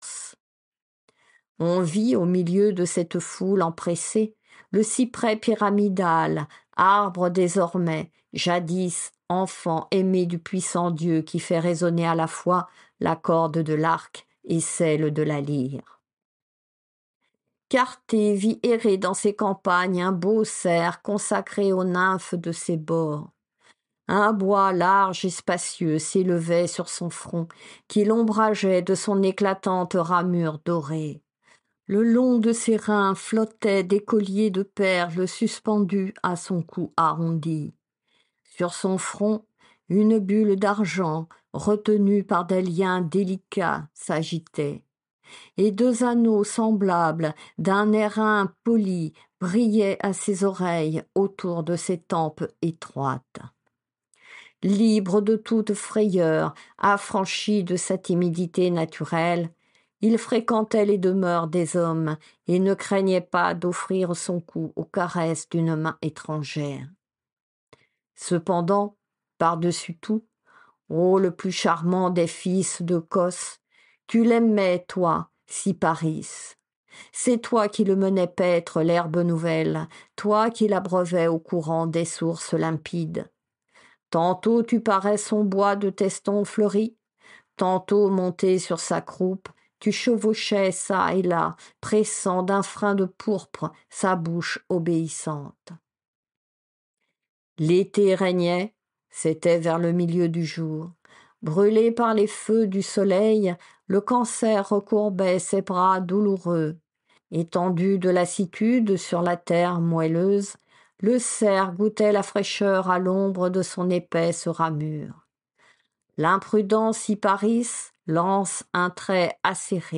Lecture de la métamorphose de Cyparisse · GPC Groupe 1